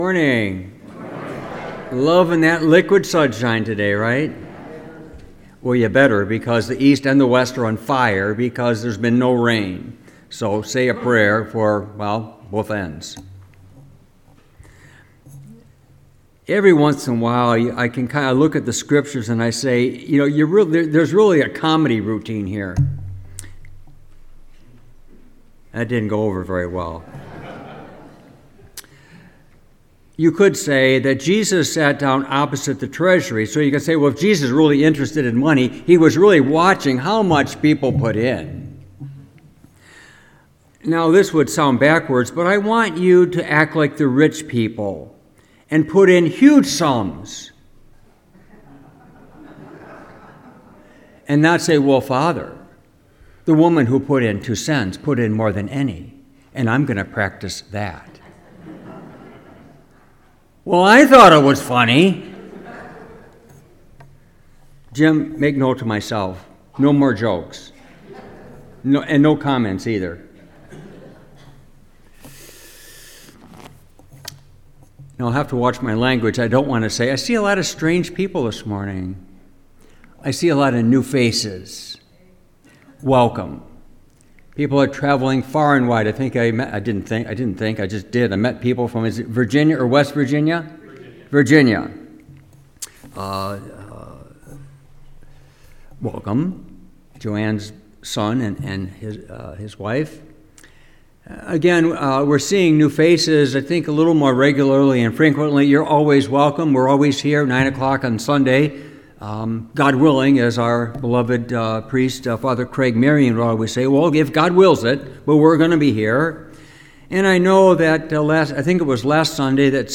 Homily, November 10, 2024